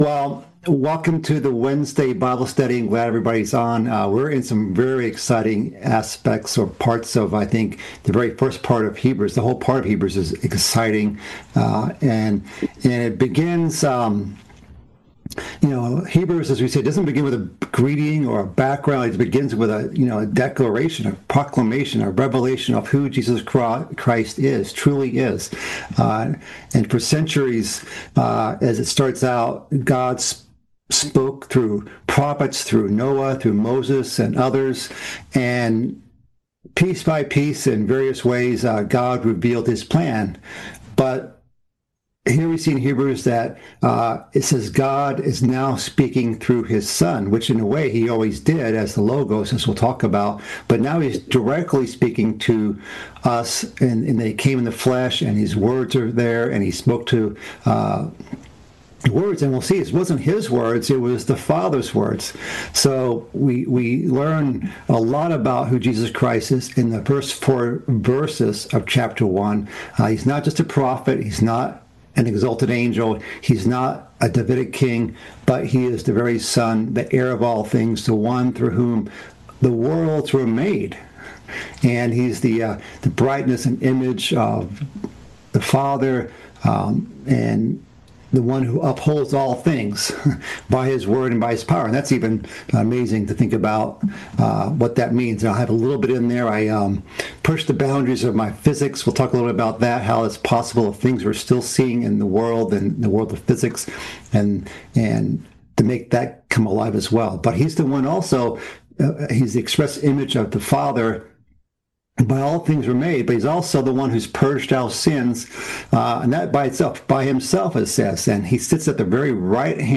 Bible Study - Hebrews Part 3 - 1:1-4